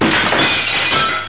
Glass-smash.wav